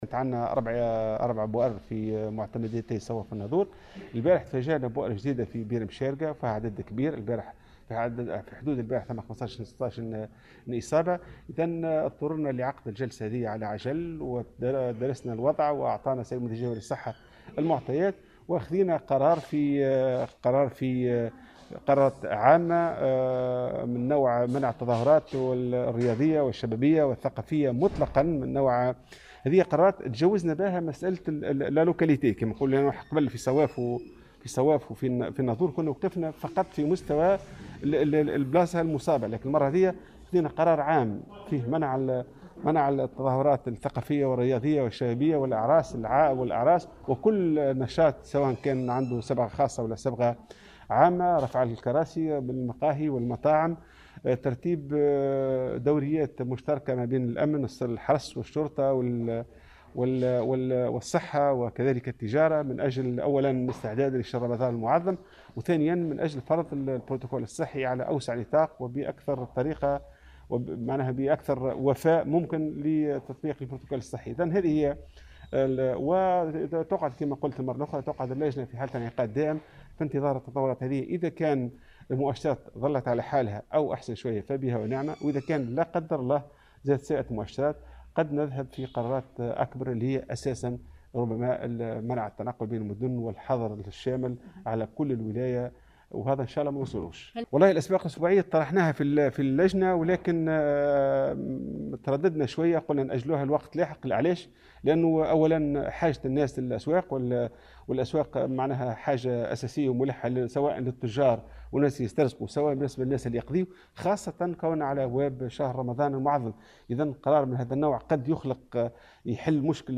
وأضاف في تصريح اليوم لمراسلة "الجوهرة أف أم" أن اللجنة الجهوية لمجابهة فيروس كورونا، قد أقرت جملة من الإجراءات الجديدة من ذلك منع التظاهرات والاحتفالات بجميع أنواعها ورفع الكراسي من المقاهي والمطاعم بكامل الولاية وتكثيف الدوريات المشتركة بين الأمن والصحة والتجارة لتطبيق الإجراءات الوقائية.